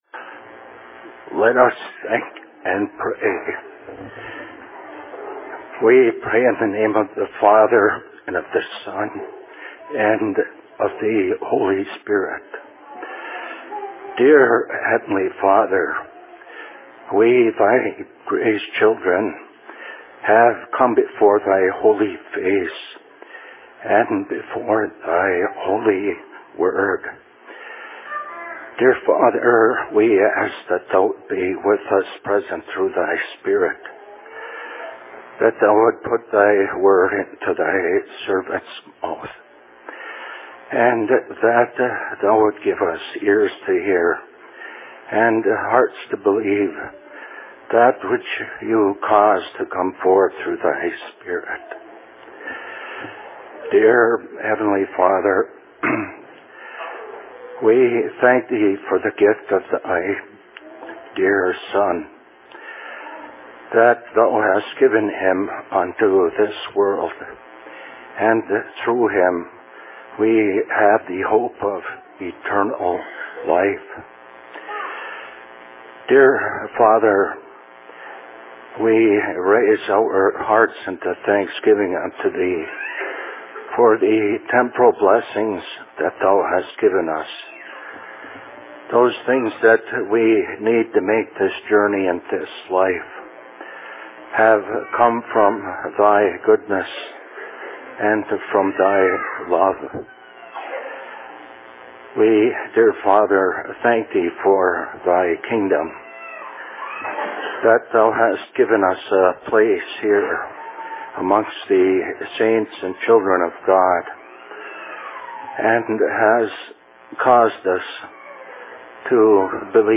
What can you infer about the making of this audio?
Location: LLC Ishpeming